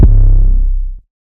WV [808] amg.wav